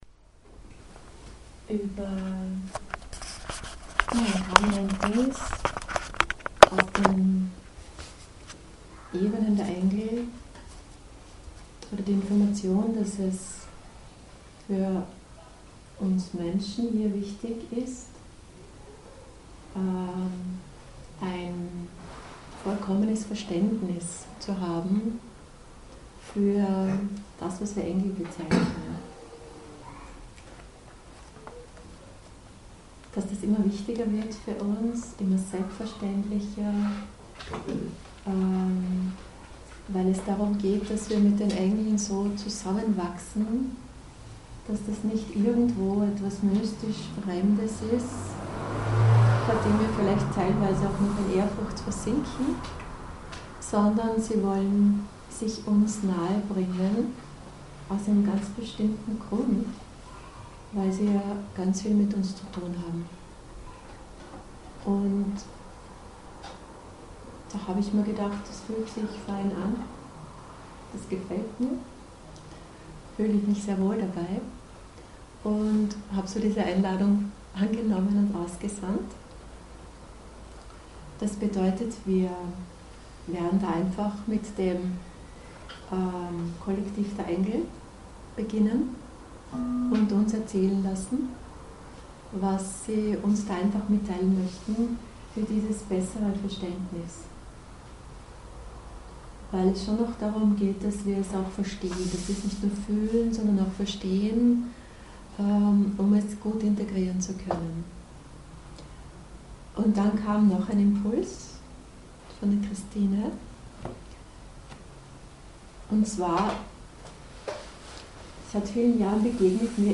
Liveaufnahme des Channels